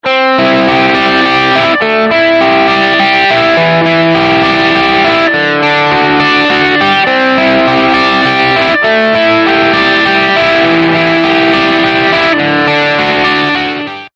GR5 Preset – RUN (Crunch)
run-crunch.mp3